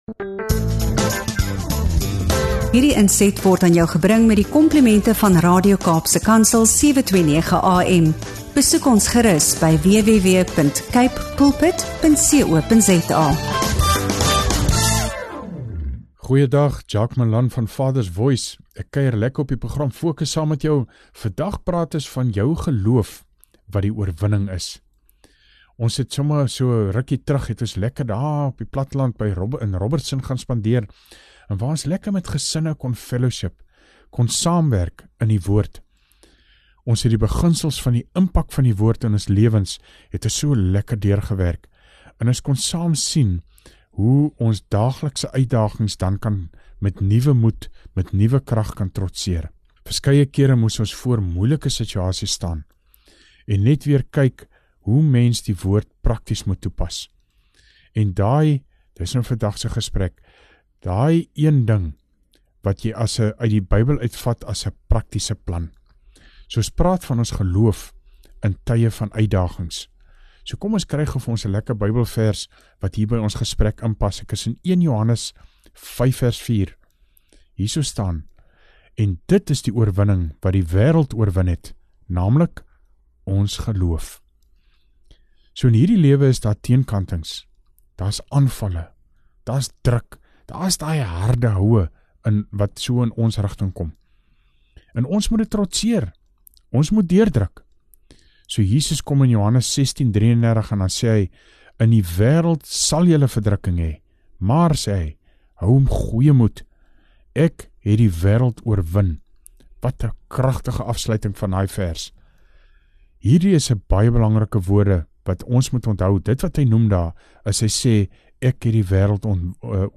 FOCUS & FOKUS DEVOTIONALS